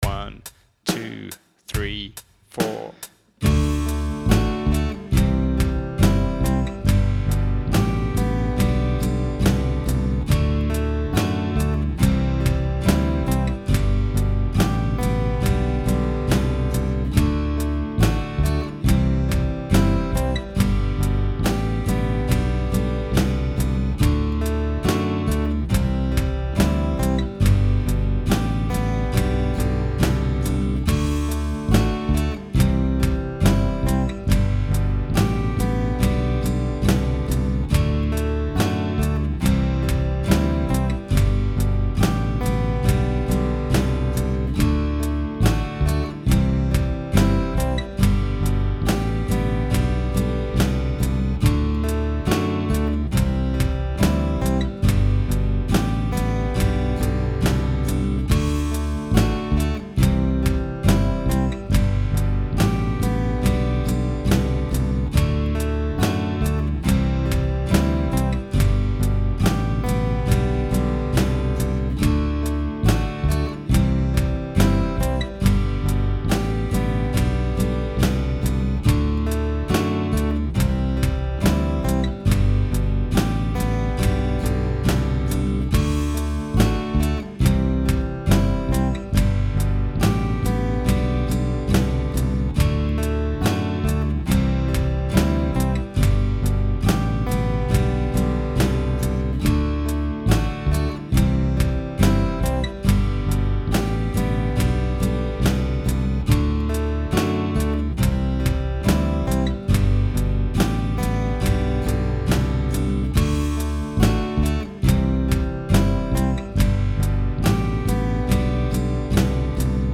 Sugar House Alhambra Backing Track | Download